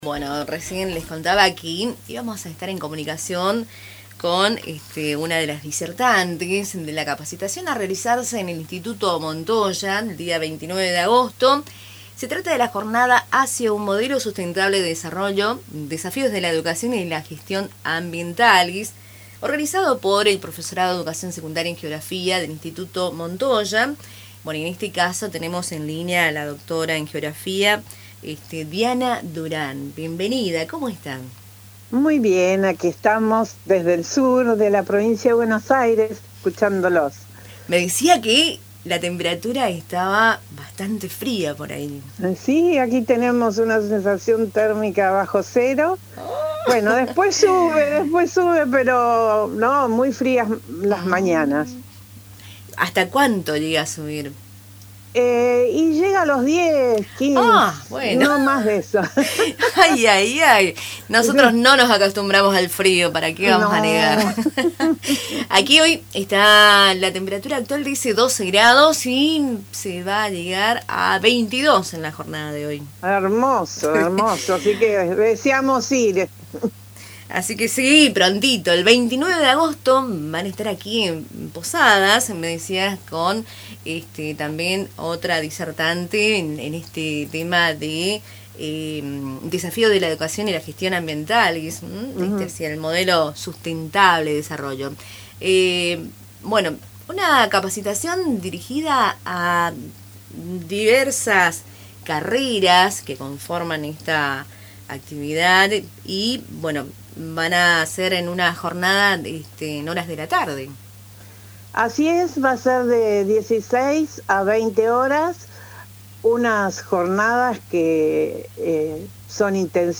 En diálogo con Radio Tupambaé